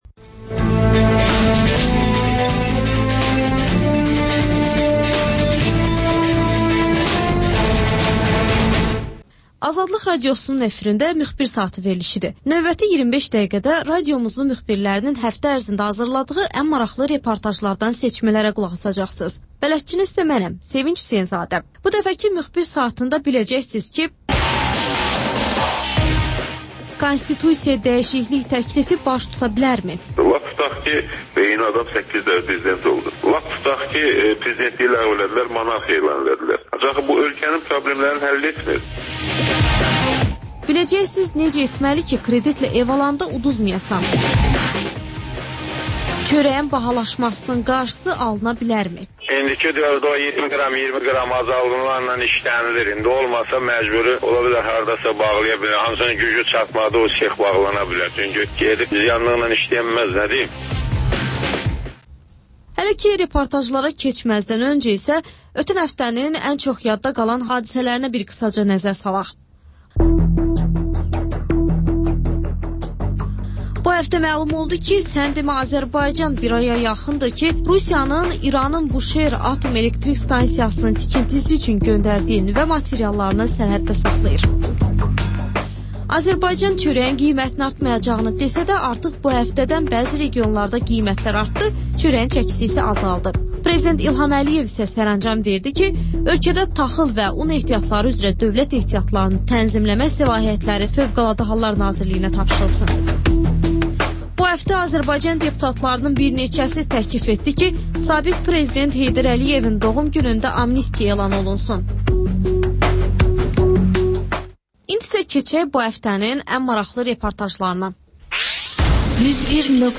Müxbirlərimizin həftə ərzində hazırladıqları ən yaxşı reportajlardan ibarət paket